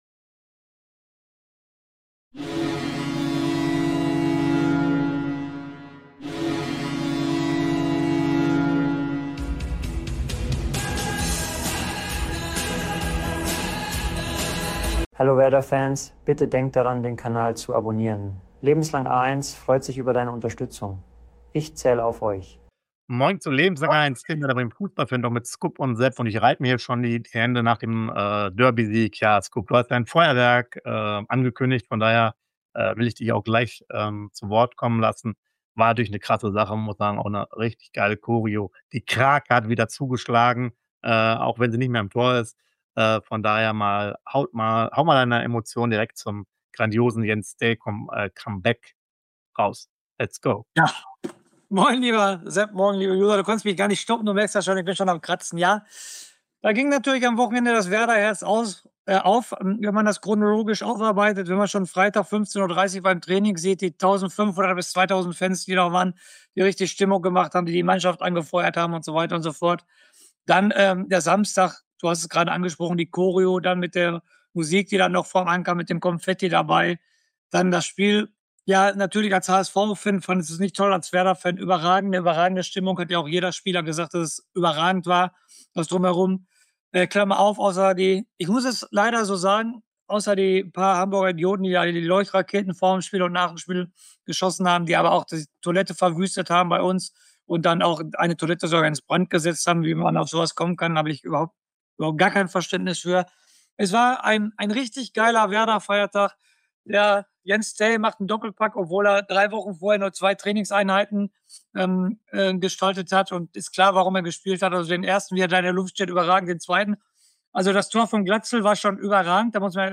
Dem Werder Bremen - Fantalk